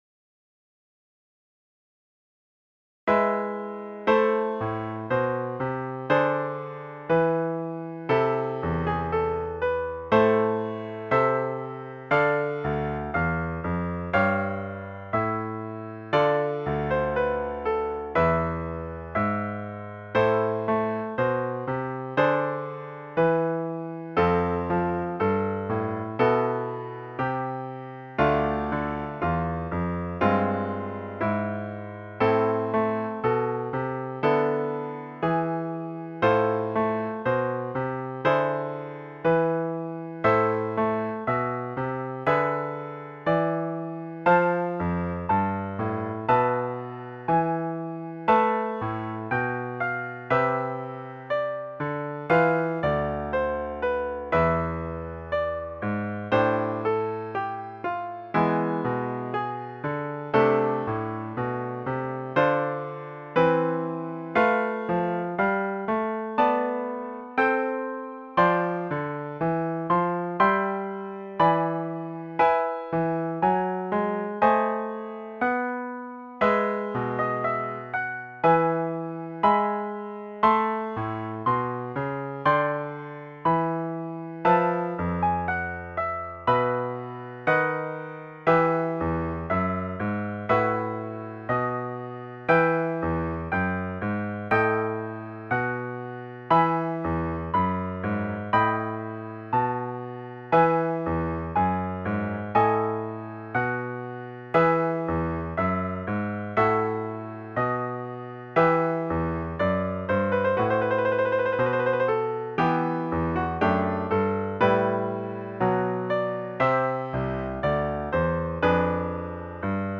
It may well be the fist movement of a piano suite.
allemande and this tempo di courante.
Allemande.mp3